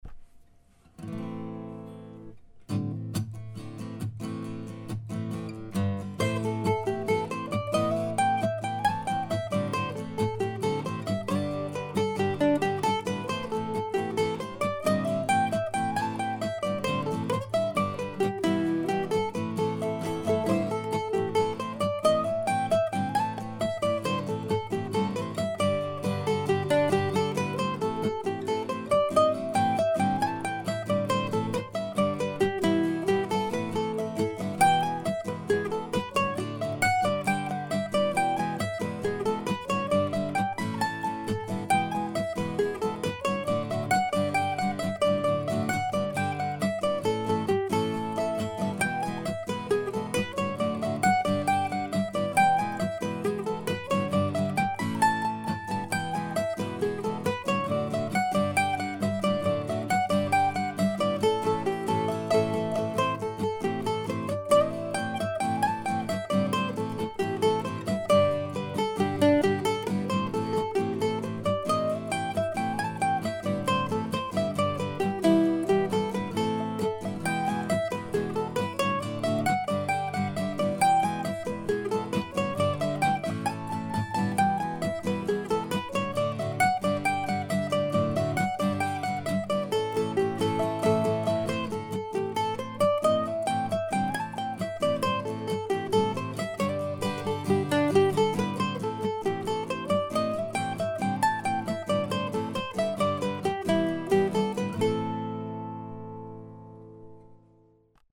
March 12, 2005 (slow reel) (
These three pieces were all recorded this morning with cold winds blowing outside.